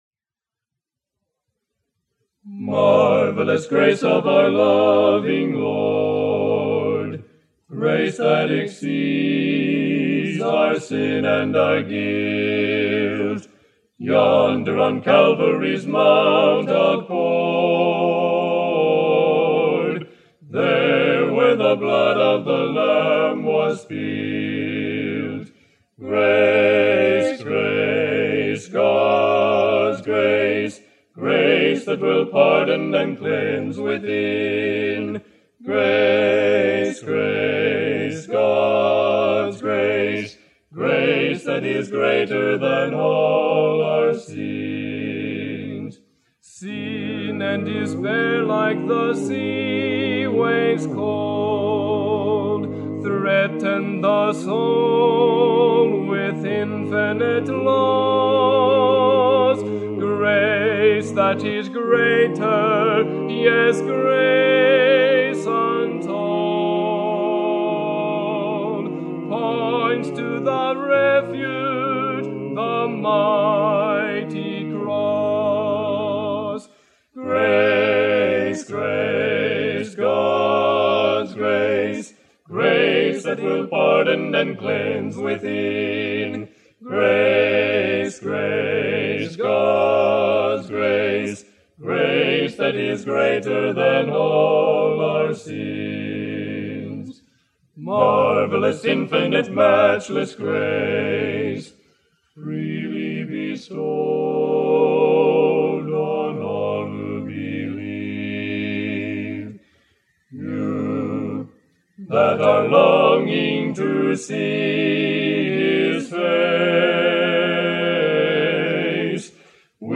This album was transferred from 1/4" magnetic audio tape. The album was recorded in 1963, but the quartet members are unknown.